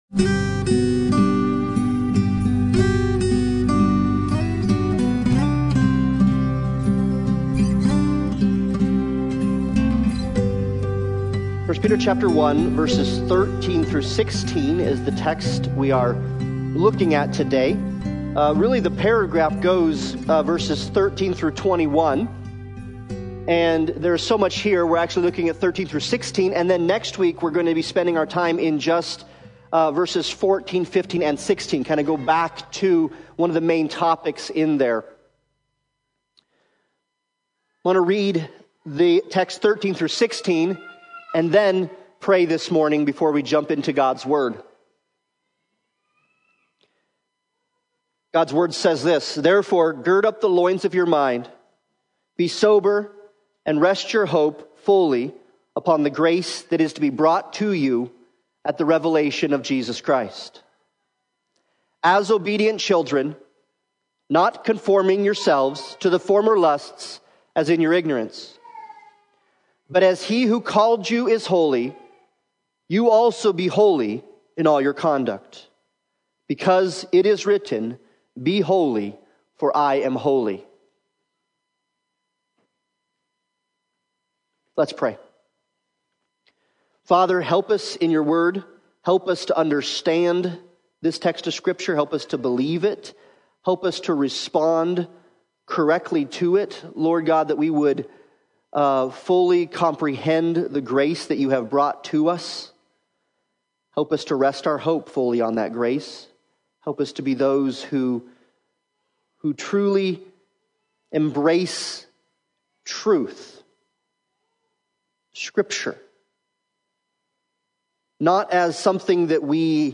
1 Peter 1:13-16 Service Type: Sunday Morning Worship « Grace Has Come